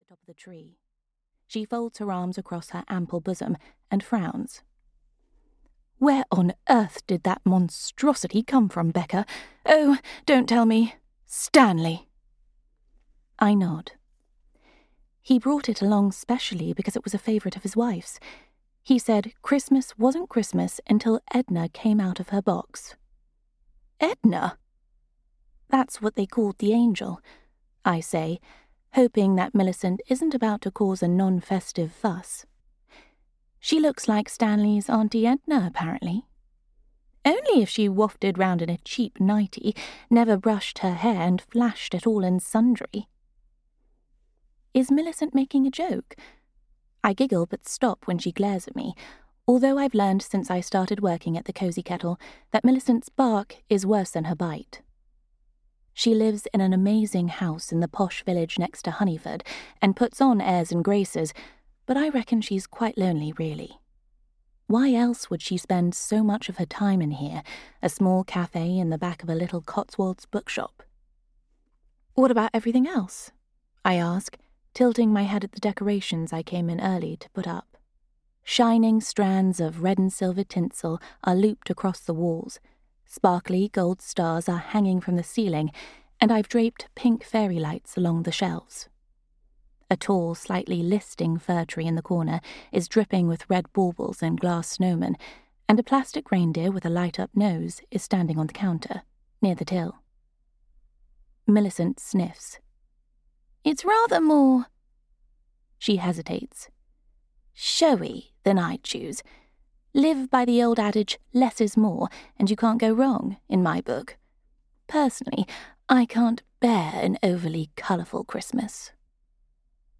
Ukázka z knihy